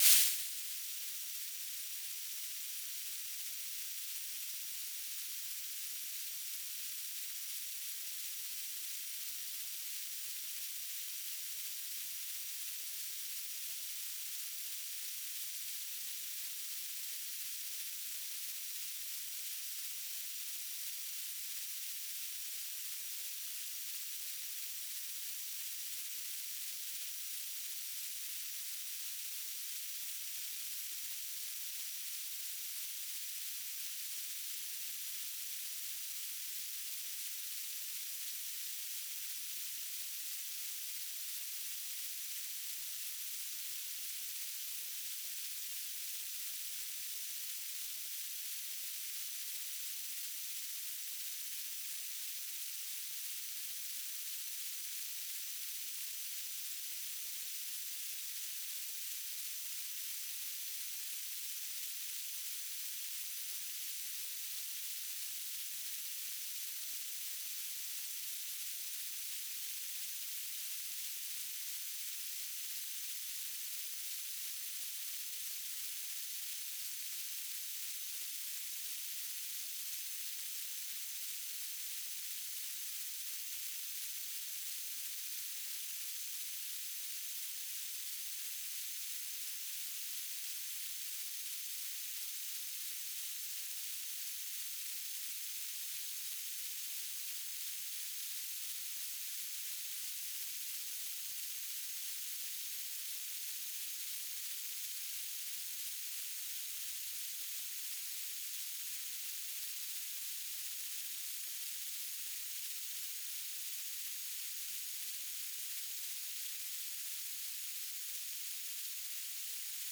"transmitter_description": "BPSK1k2 AX.25 TLM",
"transmitter_mode": "BPSK",